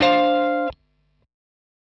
Dm7_19.wav